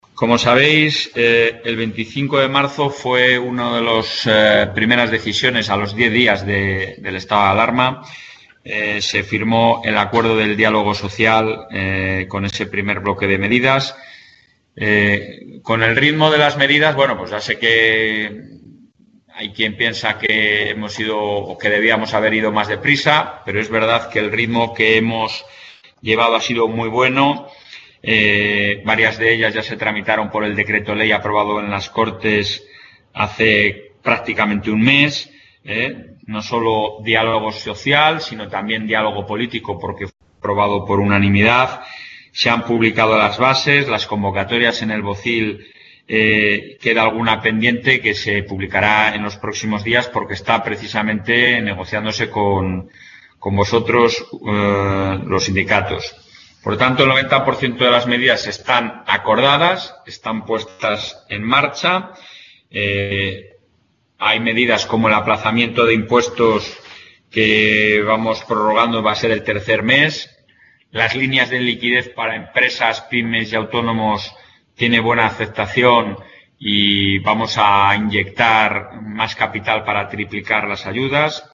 Intervención del presidente.
Durante la reunión del Consejo del Diálogo Social, que se ha celebrado esta mañana por videoconferencia, el presidente de la Junta de Castilla y León, Alfonso Fernández Mañueco, ha destacado que se están cumpliendo los acuerdos alcanzados el pasado 25 de marzo, muchas de cuyas medidas se están tramitando a través del Decreto Ley, que fue convalidado por la unanimidad de los grupos parlamentarios de las Cortes.